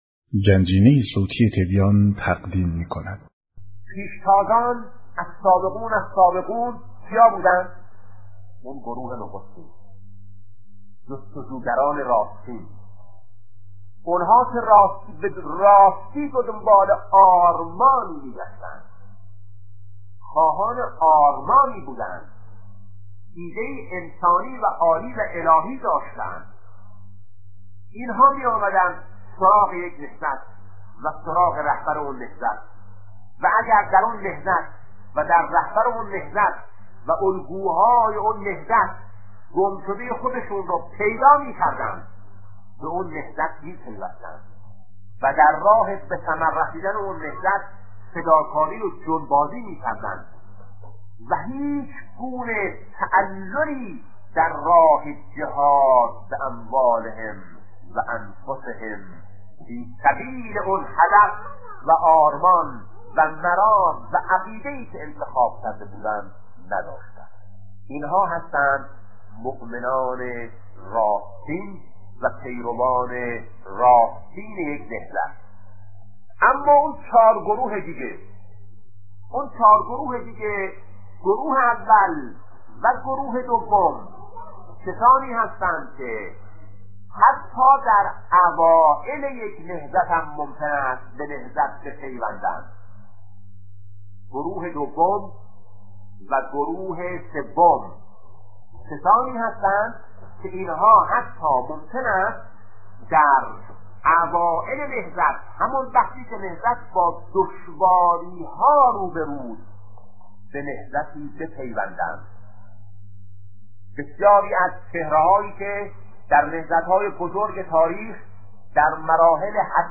سخنرانی شهید بهشتی(ره)- سه گونه اسلام-بخش‌اول